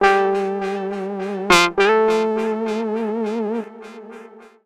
VOS SYNT 2-R.wav